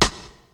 • Old School Crispy Hip-Hop Steel Snare Drum Sample F# Key 51.wav
Royality free snare single hit tuned to the F# note. Loudest frequency: 3577Hz
old-school-crispy-hip-hop-steel-snare-drum-sample-f-sharp-key-51-gZy.wav